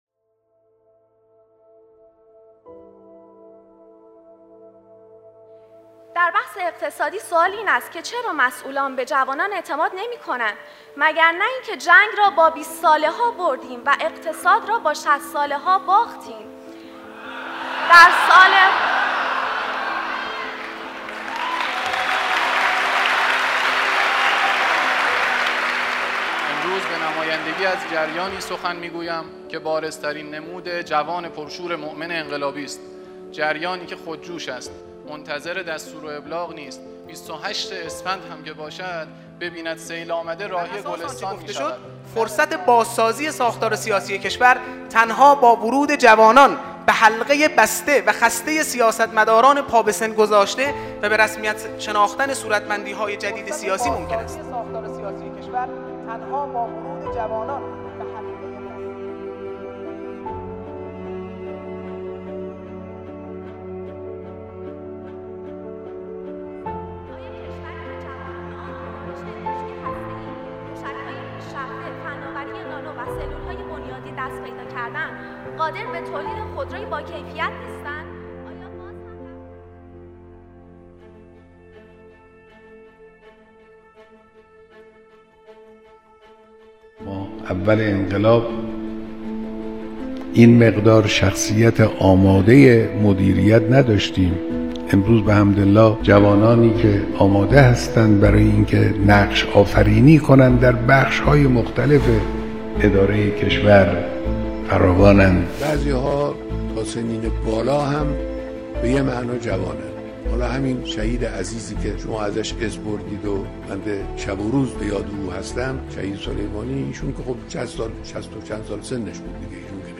نقش جوانان در اداره کشور به روایت رهبر انقلاب